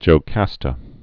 (jō-kăstə)